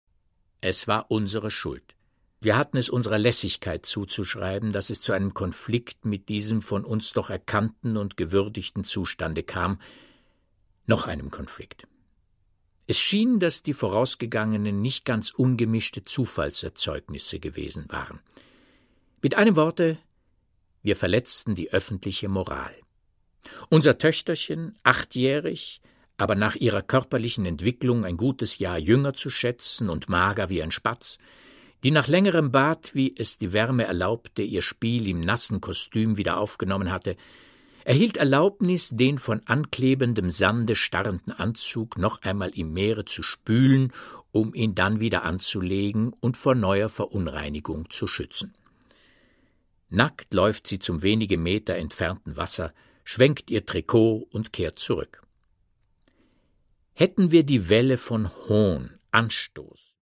Produkttyp: Hörbuch-Download
Gelesen von: Peter Matić